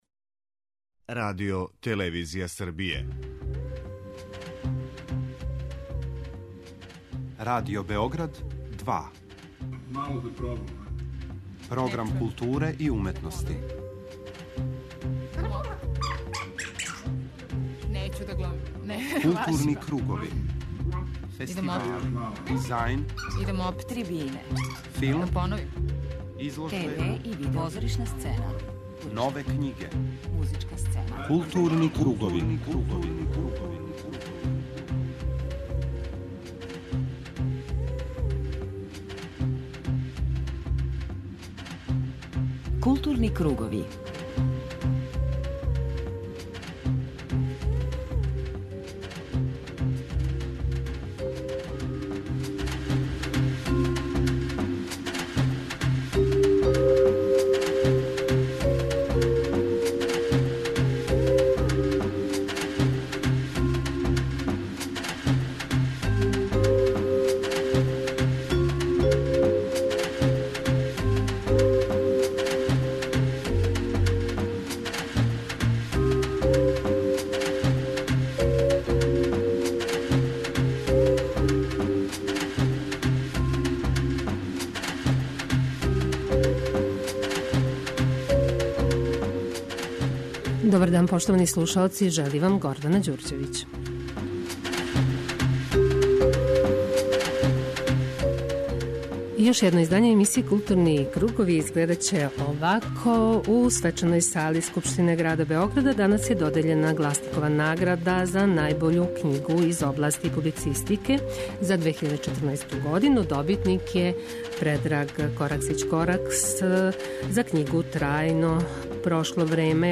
У тематском делу Маске гост ће бити глумац Љубомир Бандовић, поводом премијере представе "Разбијени крчаг", у режији Игора Вука Торбице, у Југословенском драмском позоришту, на сцени Театра "Бојан Ступица".